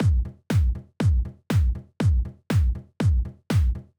Drumloop 120bpm 09-C.wav